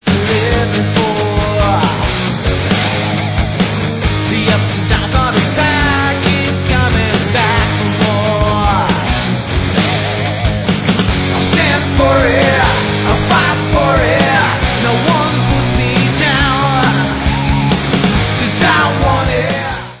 SLEAZEROCK